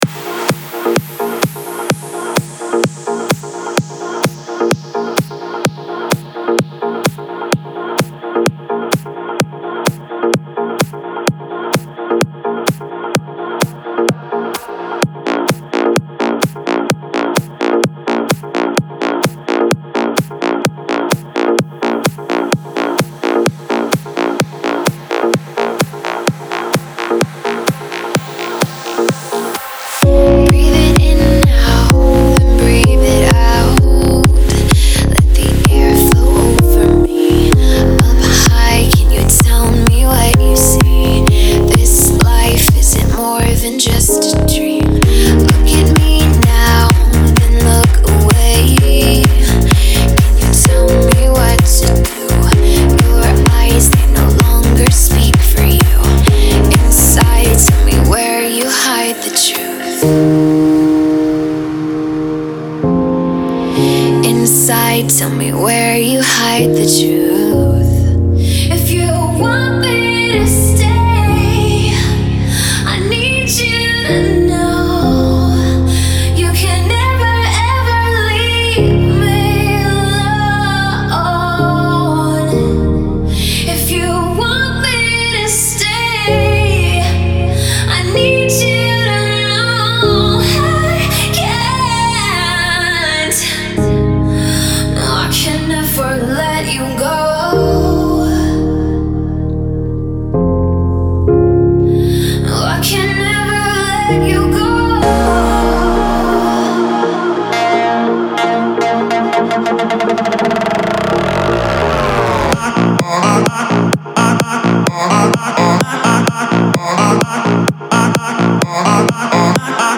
Стиль: Club House / Vocal House / Dutch House